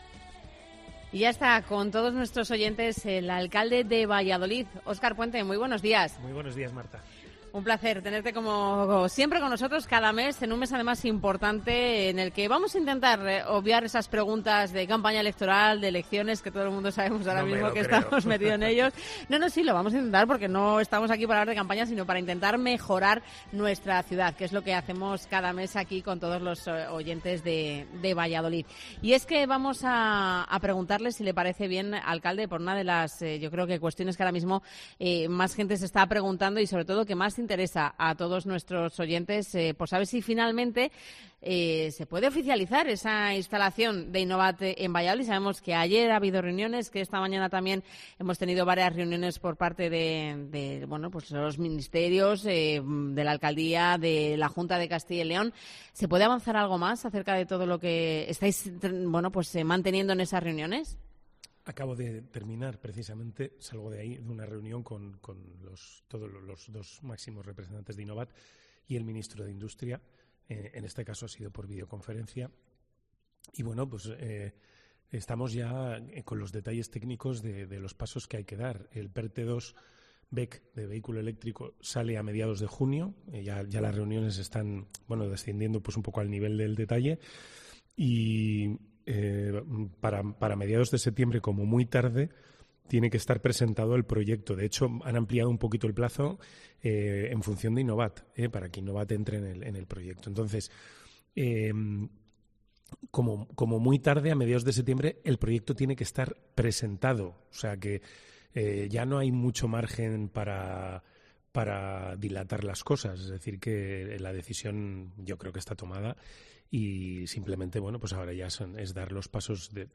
Así lo ha anunciado el alcalde de Valladolid, Óscar Puente, a su paso por el Mediodía en COPE Valladolid donde ha señalado que los fondos europeos han sido “vitales” y han hecho que la balanza se incline hacia Valladolid.